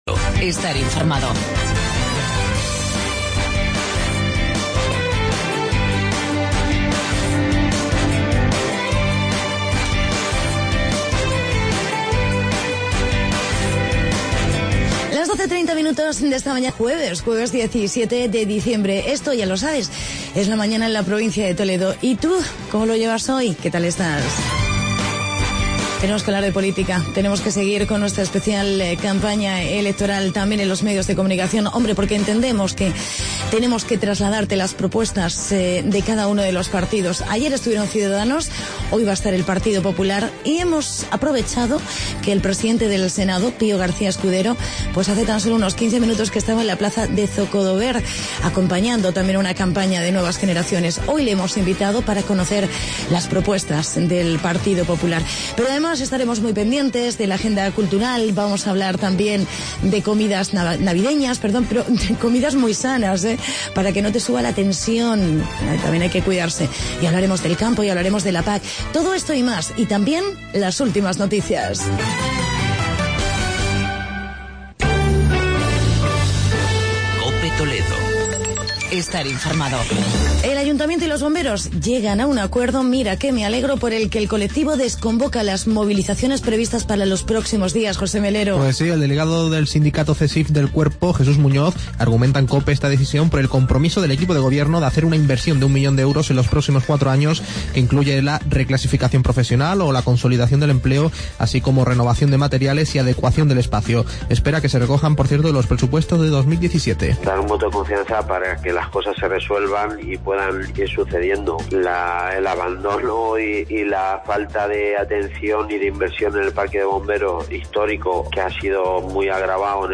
Entrevista con el presidente del Senado, Pío García Escudero y agenda cultural.